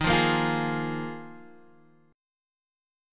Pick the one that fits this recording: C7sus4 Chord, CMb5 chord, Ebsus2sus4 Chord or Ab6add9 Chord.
Ebsus2sus4 Chord